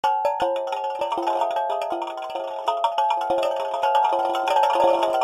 This page contains some example sounds produced by Tao instruments.
The left and right channels of the stereo output are taken from either end of this resonator. The circular sheets are excited by an algorithm which repeatedly chooses a circle at random and then uses a hammer device to produce percussive sounds.